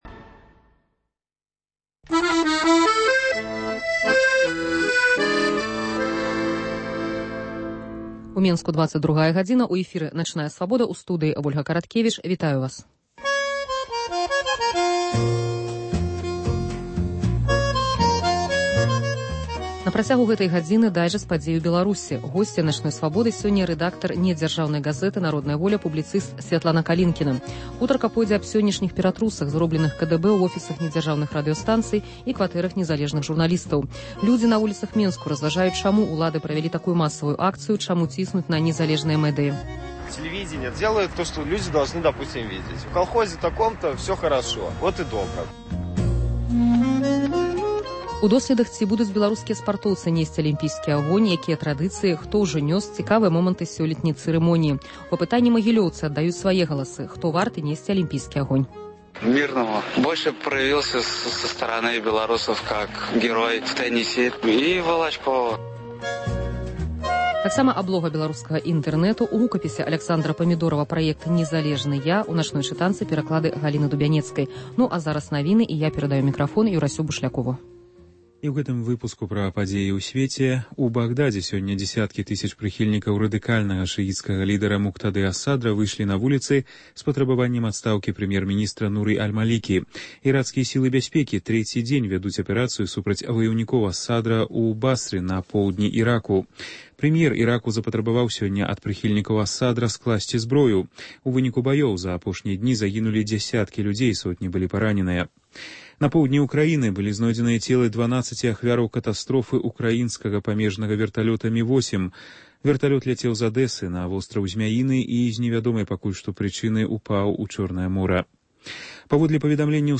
Людзі на вуліцах Менску разважаюць, чаму ўлады правялі такую масавую акцыю. * Падрабязная хроніка сёньняшніх падзей і апошнія навіны.